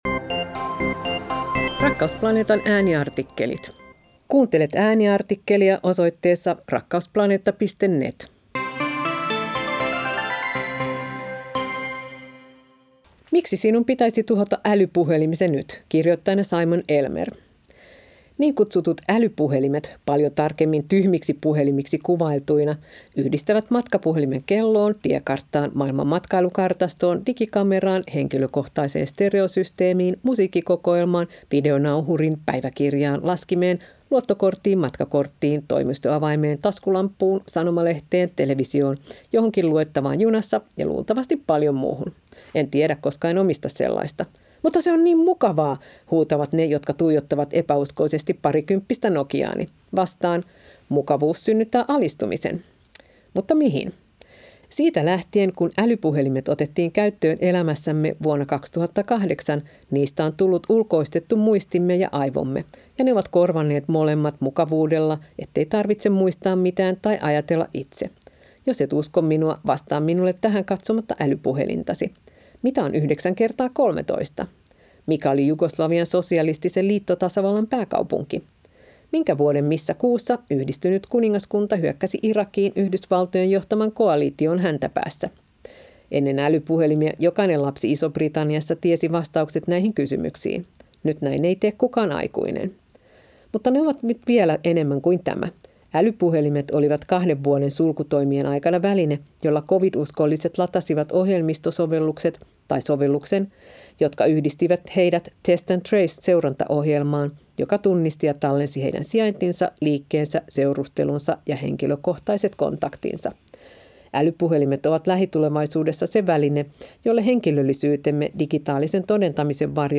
Ääniartikkelit